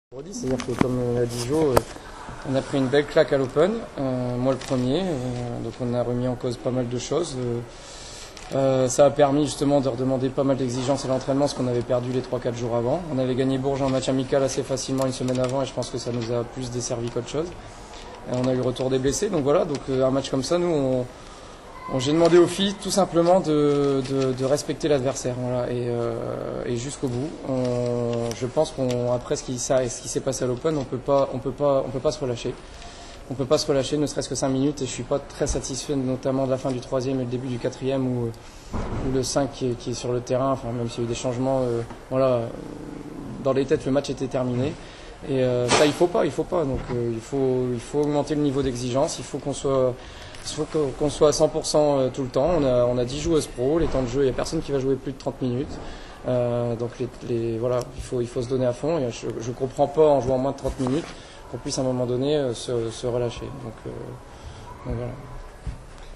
Conférence de presse